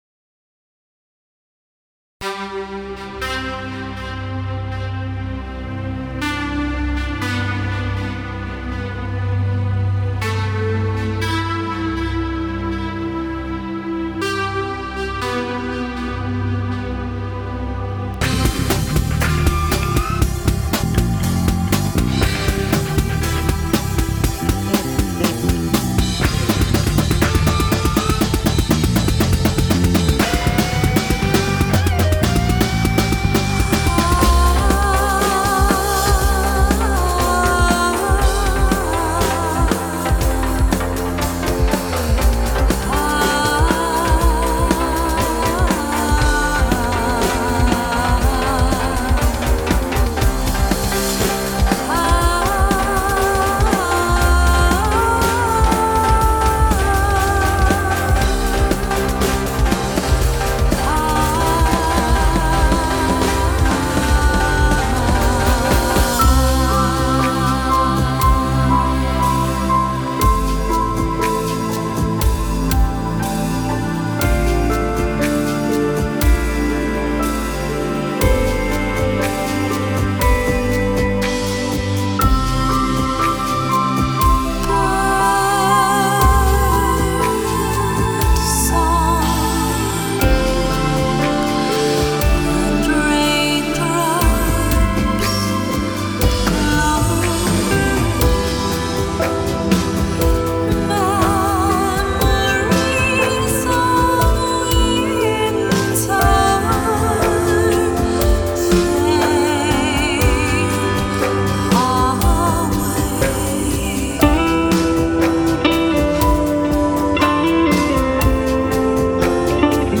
mp3,5490k] Джаз-рок